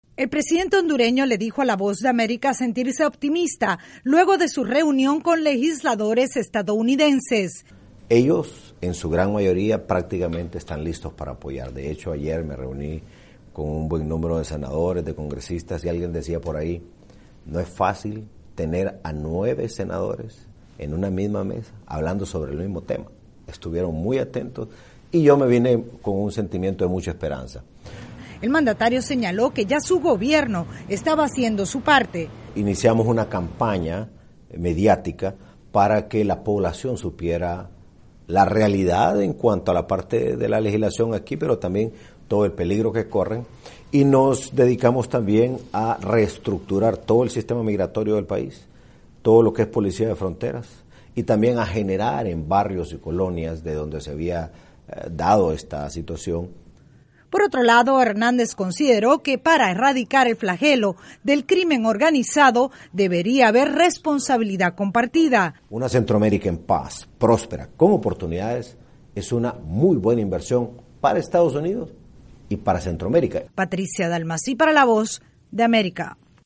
En entrevista con la Voz de América, el presidente de Honduras, Juan Orlando Hernández, se refirió a los retos y oportunidades que plantea el programa para la prosperidad, presentado en conjunto por los países del triángulo norte centroamericano.